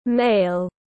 Nam giới tiếng anh gọi là male, phiên âm tiếng anh đọc là /meɪl/.
Male /meɪl/